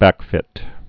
(băkfĭt)